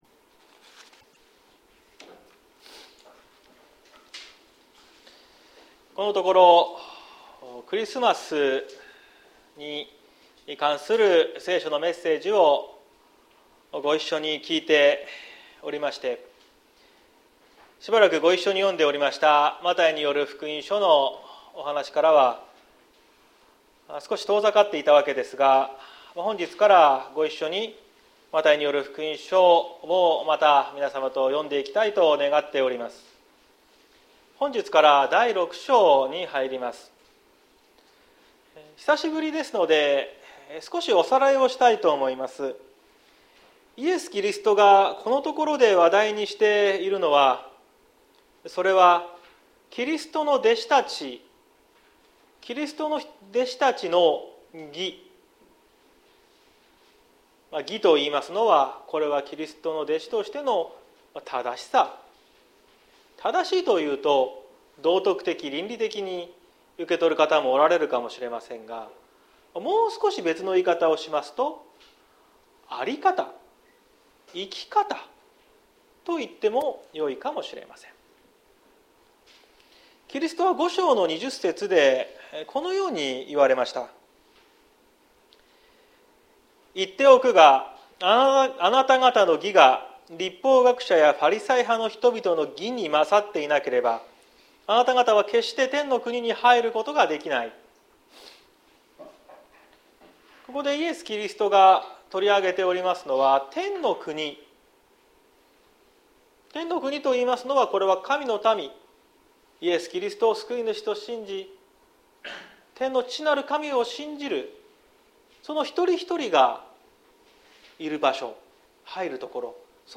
2023年01月08日朝の礼拝「良いことをする時には」綱島教会
綱島教会。説教アーカイブ。